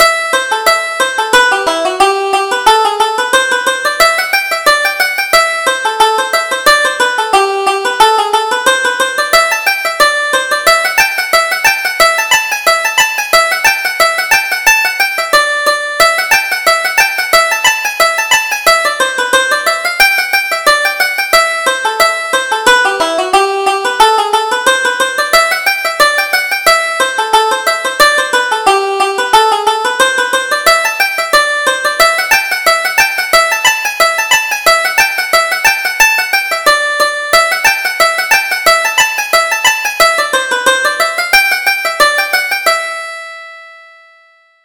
Reel: Rolling Down the Hill